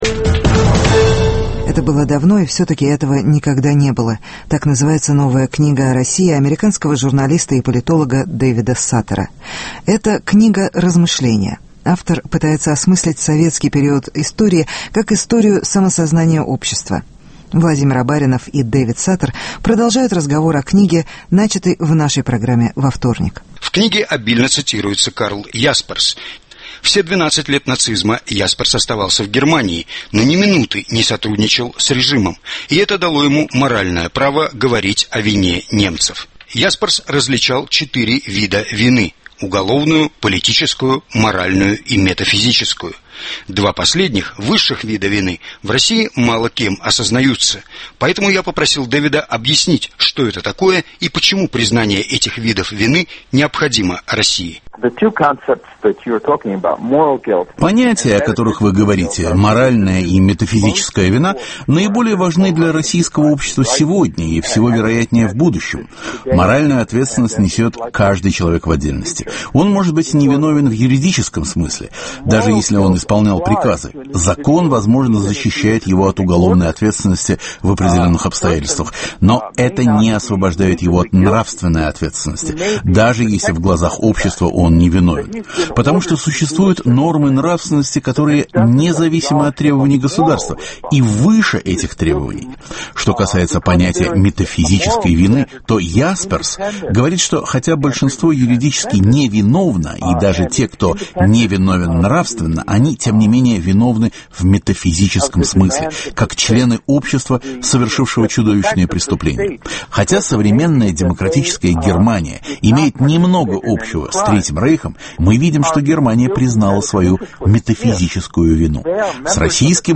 Продолжение беседы с политологом Дэвидом Саттером